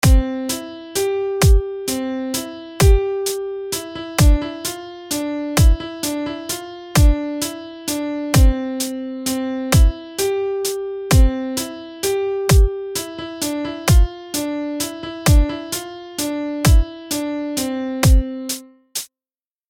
Zkusme nyní opustit dvojnásobky a změnit bicí tak, aby jeden takt sestával ze tří dob, nikoliv ze čtyř (přičemž délka jedné doby i rychlost skladby zůstanou stejné).
To zní velmi divně.